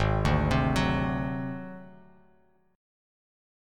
G#7b5 chord